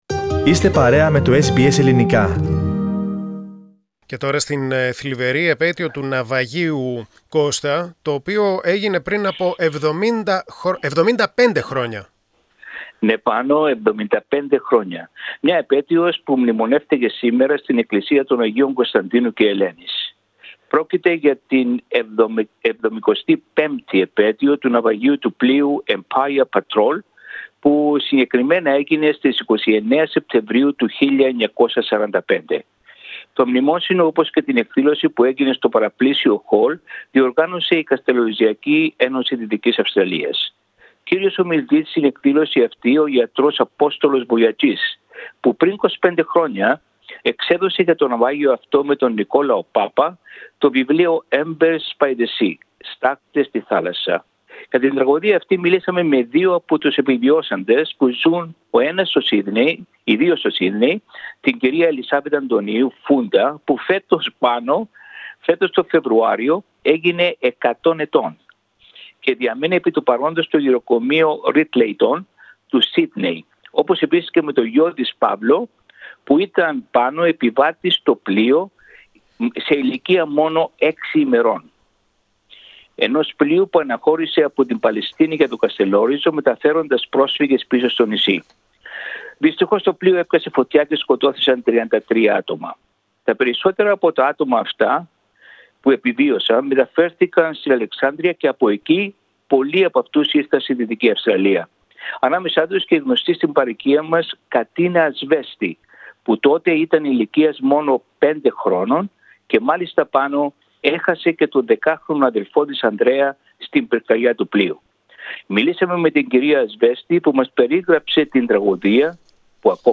Για την τραγωδία αυτή, το SBS Greek μίλησε με δύο από τους διασωθέντες του ναυαγίου που ζουν στο Σύδνεϋ.